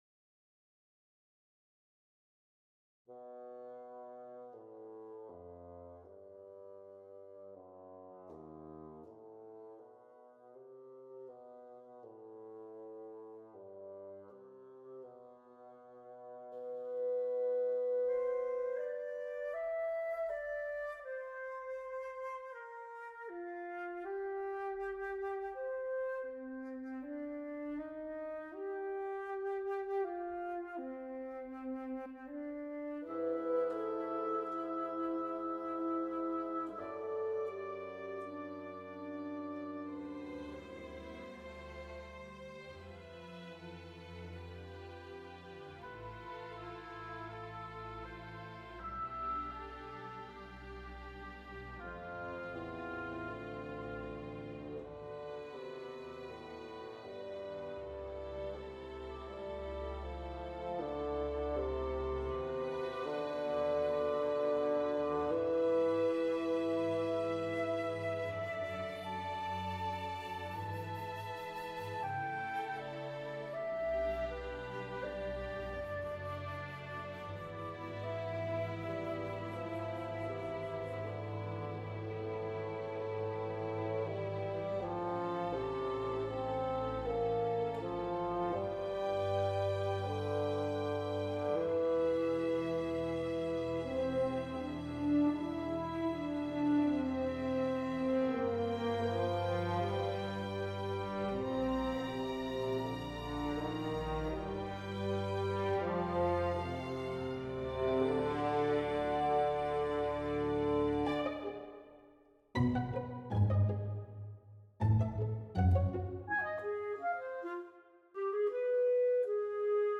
Miniature concerto for bassoon
I've recently been asked to write a 5 minute piece for chamber orchestra, prominently featuring the bassoon. A sort of mini bassoon concerto if you will, for a concert next summer.